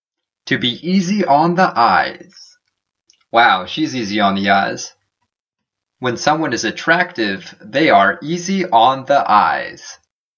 英語ネイティブによる発音は下記のリンクをクリックしてください。